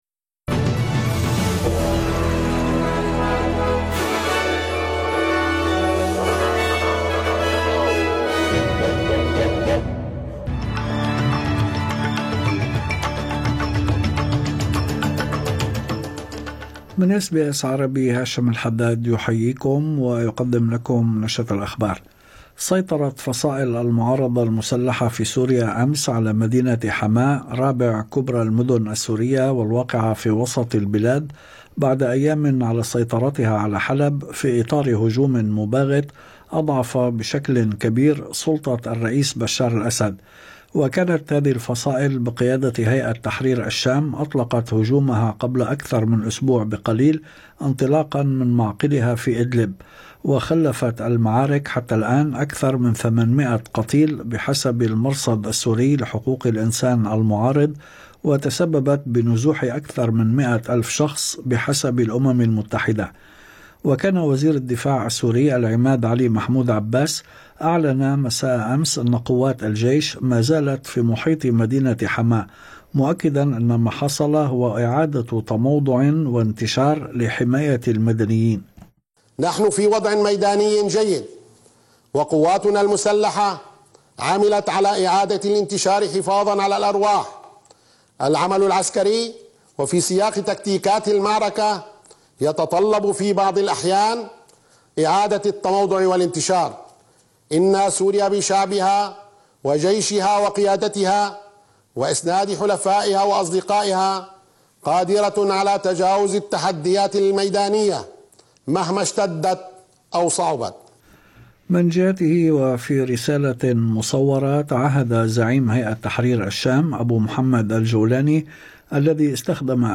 نشرة أخبار الظهيرة 6/12/2024